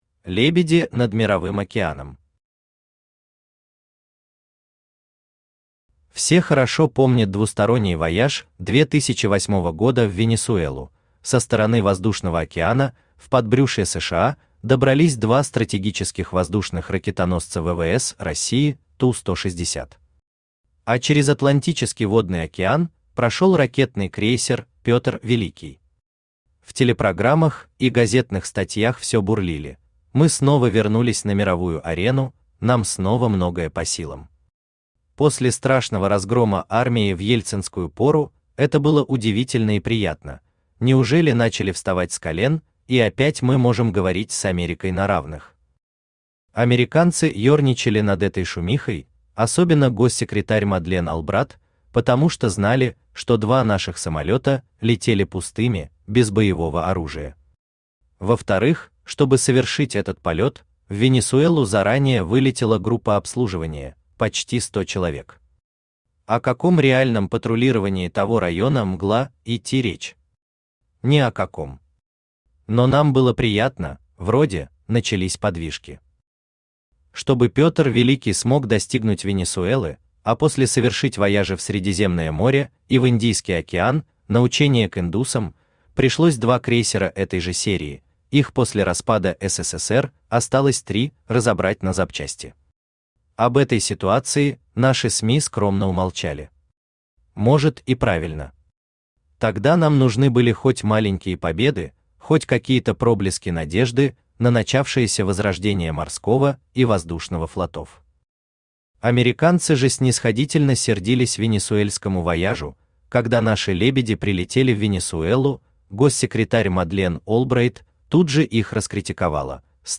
Аудиокнига Лебеди над Мировым океаном | Библиотека аудиокниг
Aудиокнига Лебеди над Мировым океаном Автор Алексей Николаевич Наст Читает аудиокнигу Авточтец ЛитРес.